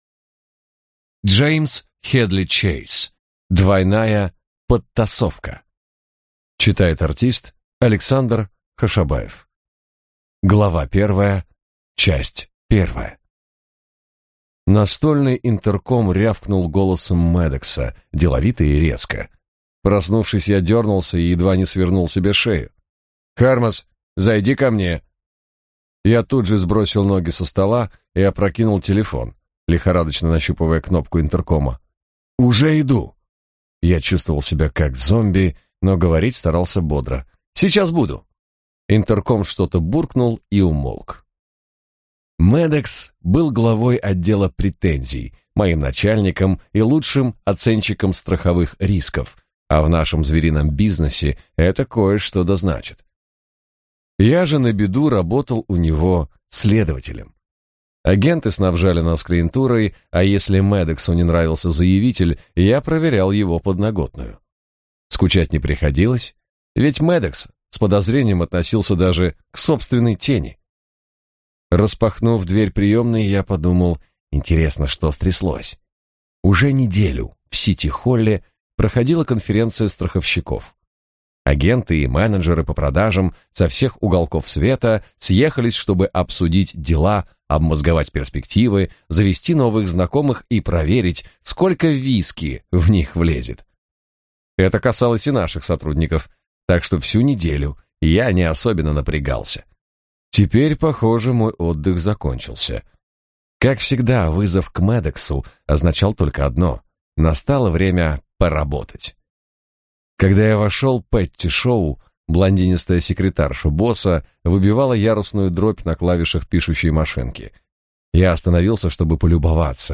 Аудиокнига Двойная подтасовка | Библиотека аудиокниг